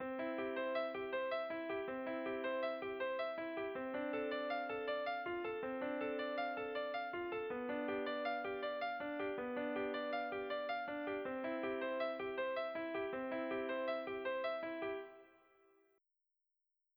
version à 5 temps